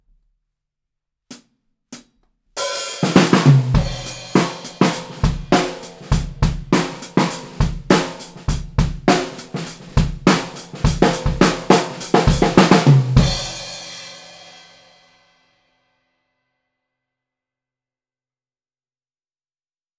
すべて、EQはしていません。
④ドラマー目線
最後に、ドラマーの目線の位置にマイキングしてみました。
先ほどと似たようなサウンドですが、こちらの方が低音成分が多いですね！